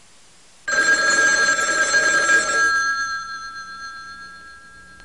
Phone Sound Effect
Download a high-quality phone sound effect.
phone.mp3